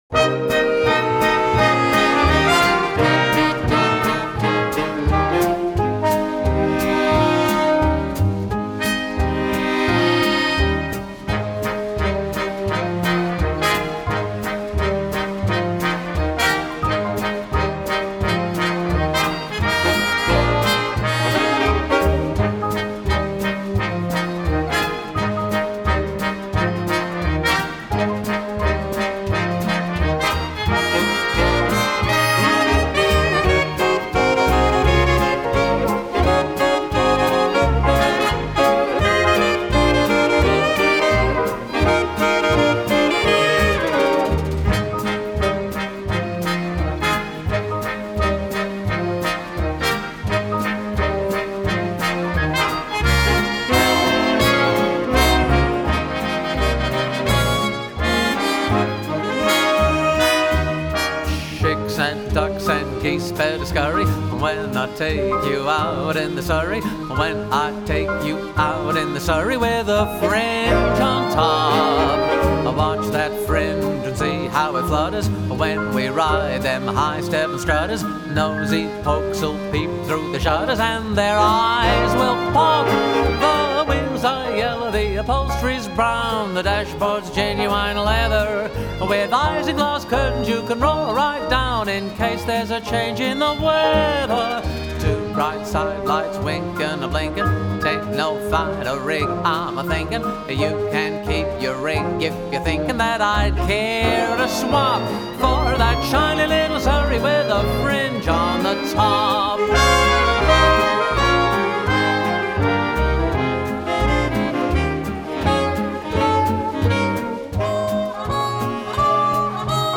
swing version
1943   Genre: Musical   Artist